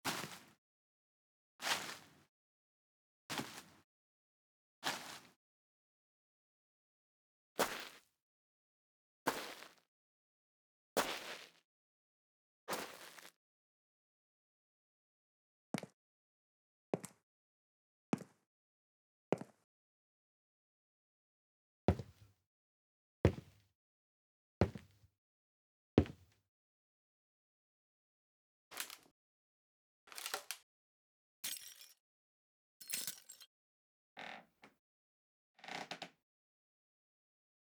Footsteps
A sound library about footsteps on various surfaces.
In this release you will find a series of one-shot step variations and some separate layers for added textures including:
– 10 Grass Footstep.
– 10 Sand Footstep.
– 29 Stone Footstep.
– 10 Wood Footstep.
– 30 Texture Layers including Squeaks, Glass, and Dirt.
All samples were recorded at 96kHz 24 bit with a Zoom H5 and Sennheiser ME 67.
Footsteps.mp3